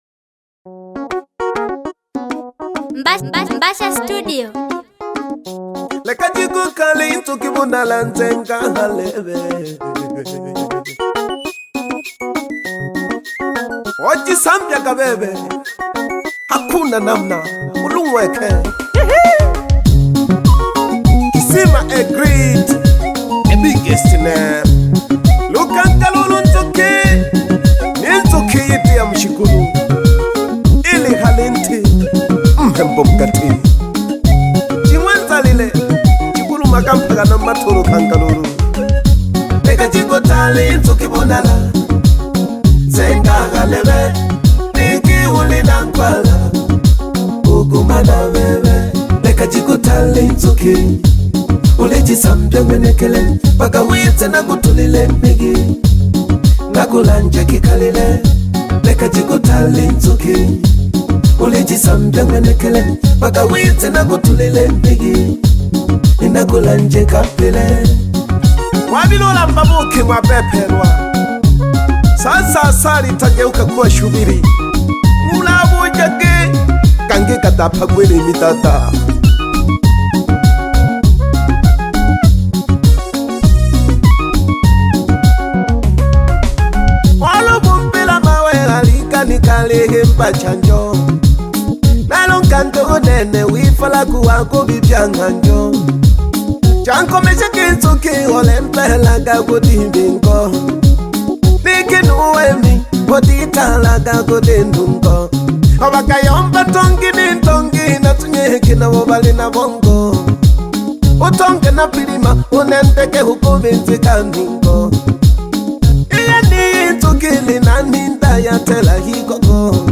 sukuma tradition song